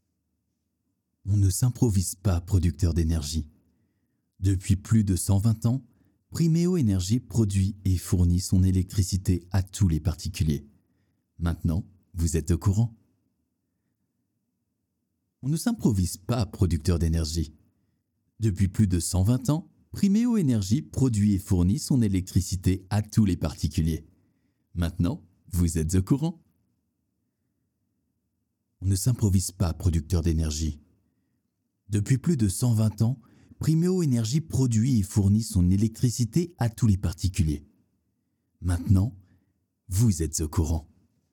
Bandes-son
25 - 50 ans - Baryton-basse